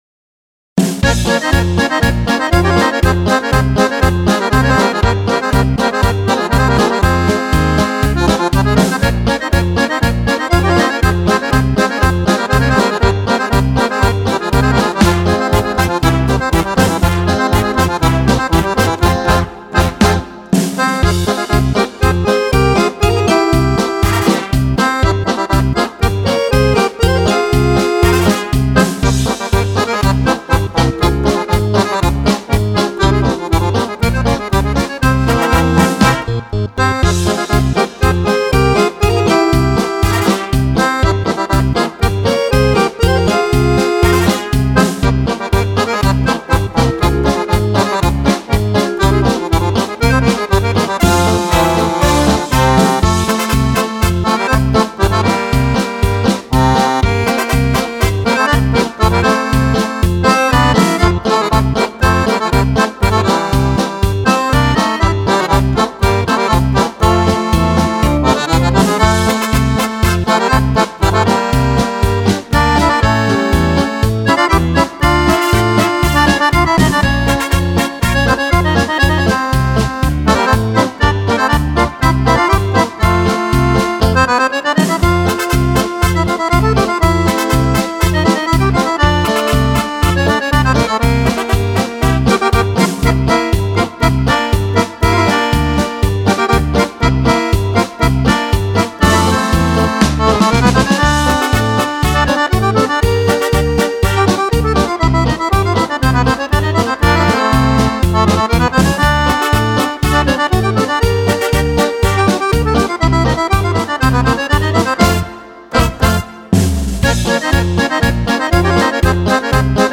Paso-doble